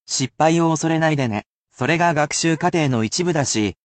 I can only read it at one speed, so there is no need to repeat after me, but it can still assist you in picking out vocabulary within natural speeds of speech.